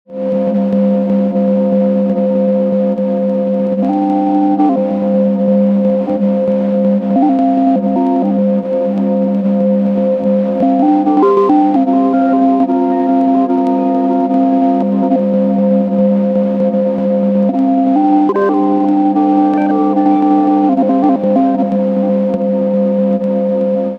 Basically rhythmic noise coming from Labyrinth going into a Echosystem preset I made with reverse and whiskey in dual parallel mode, adjusting thing 2 for whiskey while playing.